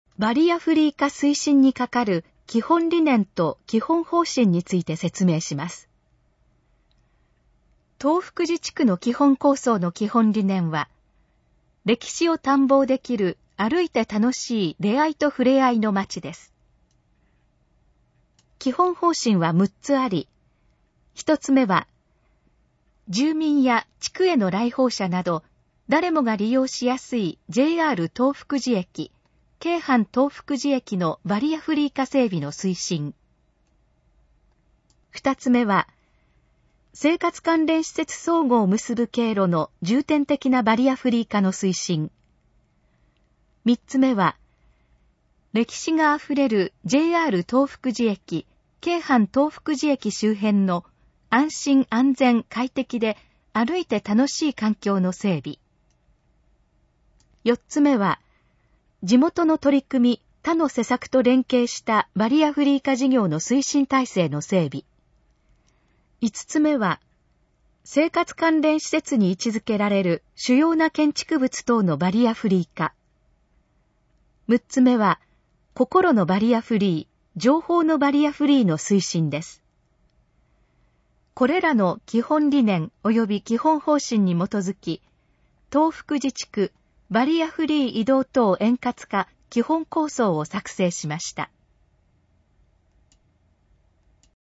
このページの要約を音声で読み上げます。
ナレーション再生 約373KB